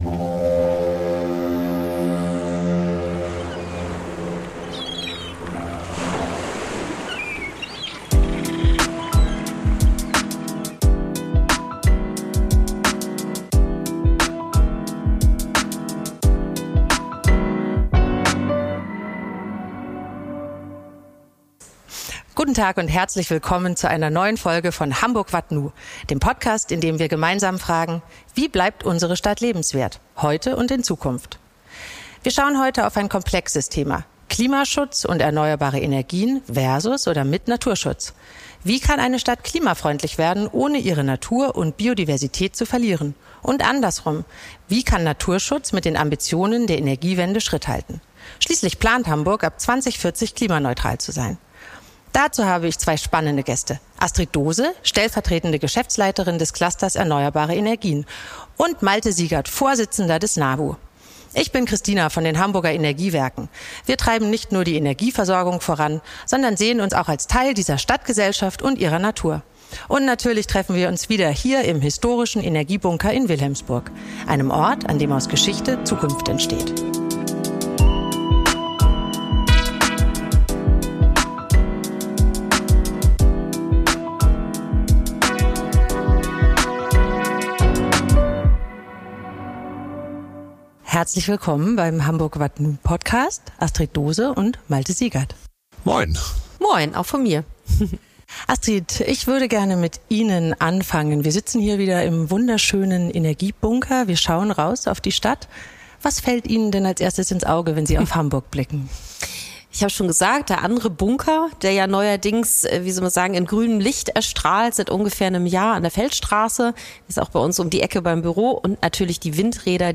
Ein Gespräch über Kompromisse, Beteiligung und die Chancen einer naturverträglichen Energiewende. Aufgenommen im Energiebunker in Wilhelmsburg – mit Blick auf eine Stadt, in der Klimaschutz und Naturschutz gemeinsam gedacht werden können.